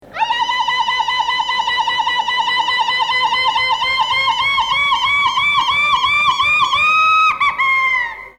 Irrintzia
Irrintzia.mp3